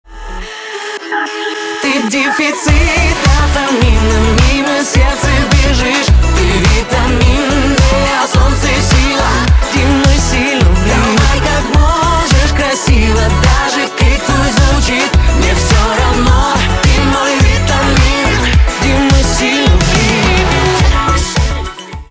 поп
битовые , басы